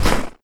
STEPS Snow, Run 10.wav